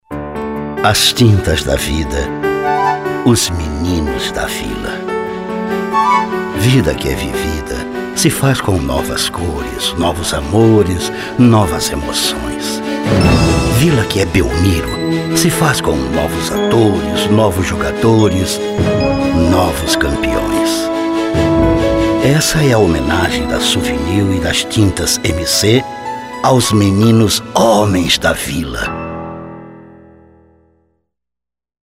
Sua voz é uma das mais versáteis da dublagem brasileira, pois ele possui quatro ou mais tipos de vozes diferentes, desde a fanhosa até seu famoso vozeirão profundo.